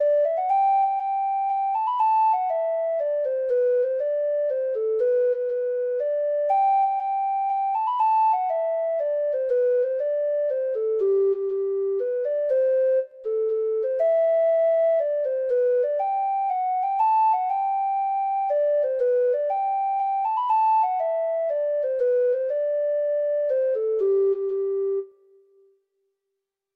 Free Sheet music for Treble Clef Instrument
Traditional Music of unknown author.
Irish